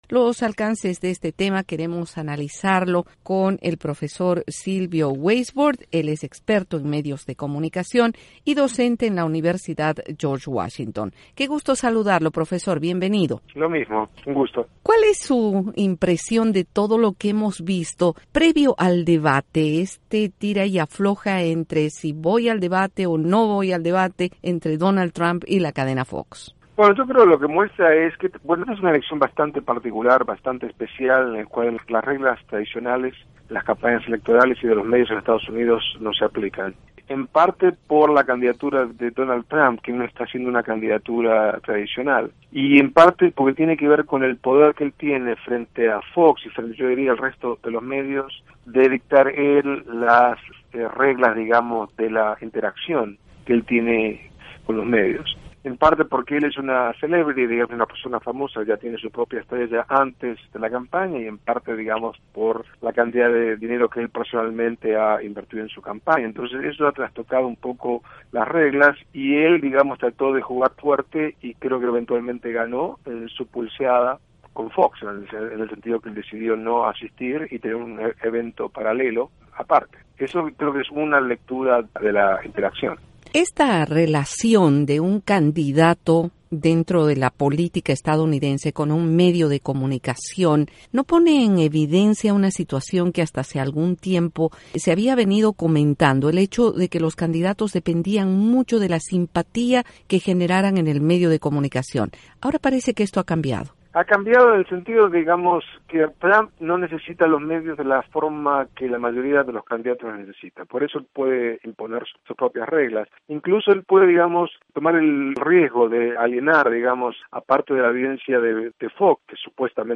Entrevista con el experto en medios